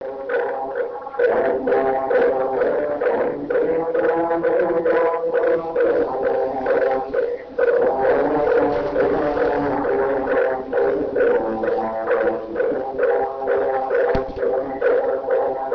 旅館となりのチョゲ寺からの
木魚とお経の声で4時過ぎに目が覚める。